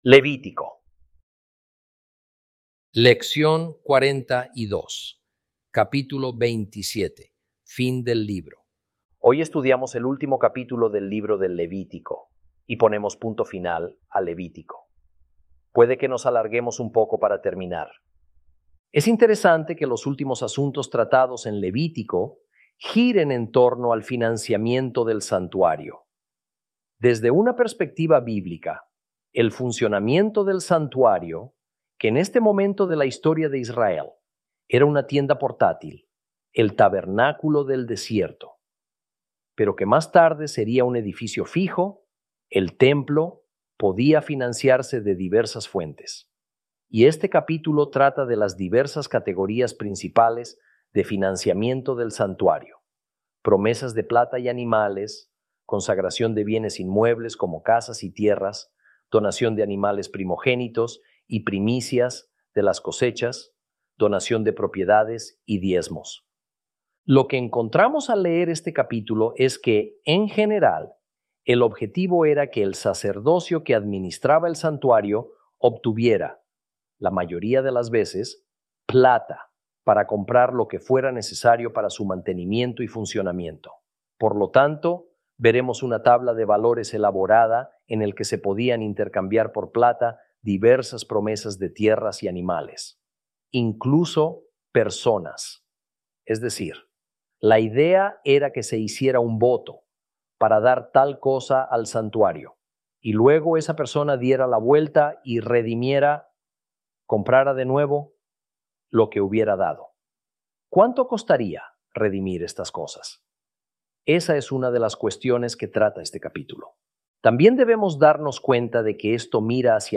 Lección 42 – Levítico 27